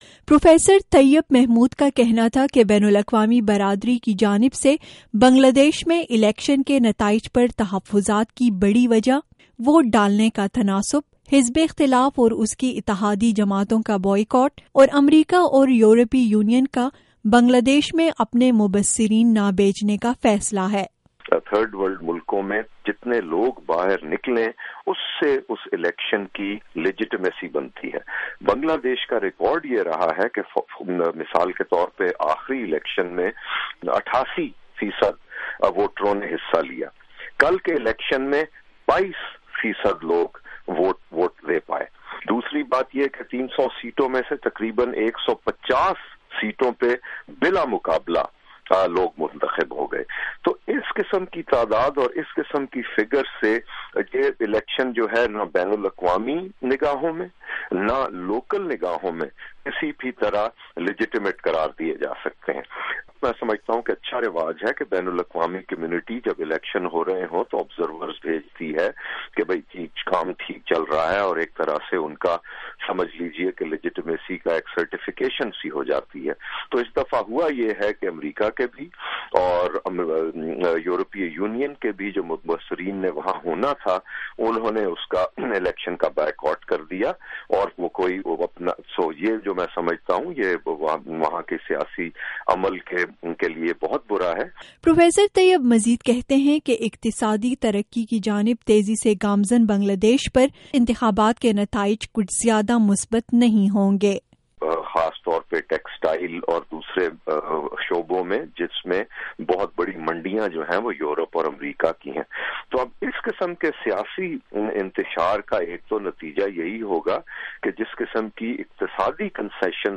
خصوصی بات چیت